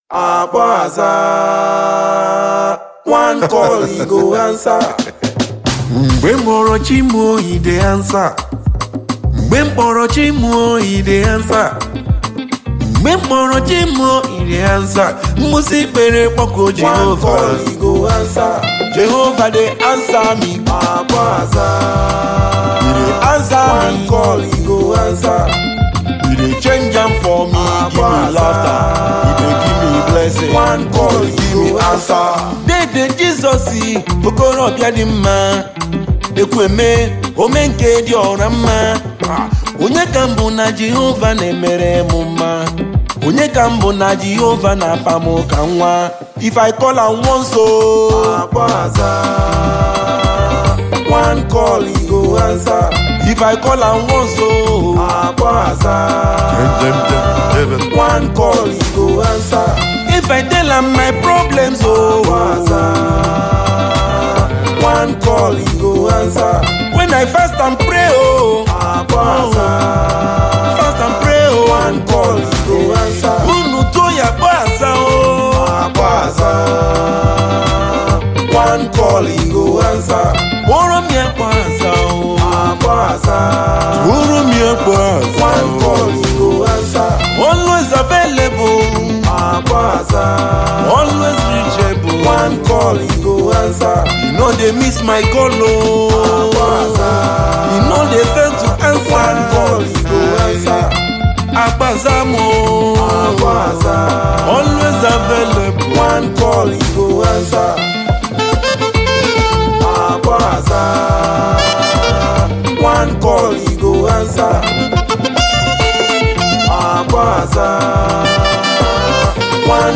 Gospel
songwriter and saxophonist.
traditional African rhythms